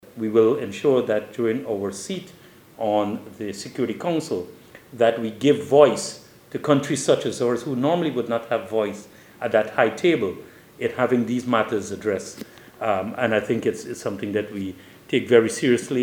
In an interview with NCN, Secretary Persaud underscored Guyana’s proactive stance in providing global leadership, advocating for these pressing issues, and mobilizing the international community.